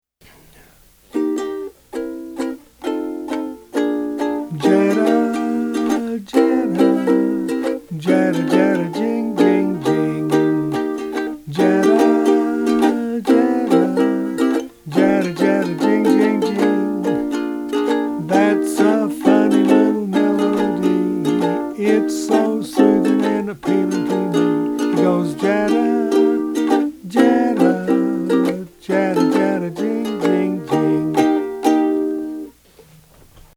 Ukulele mp3 songs from sheet music
Please ignore any sour notes.